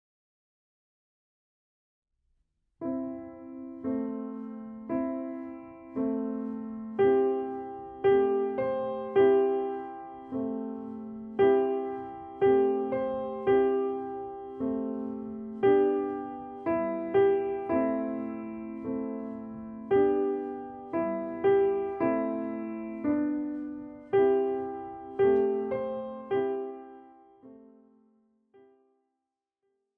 41 leichte Klavierstücke
Besetzung: Klavier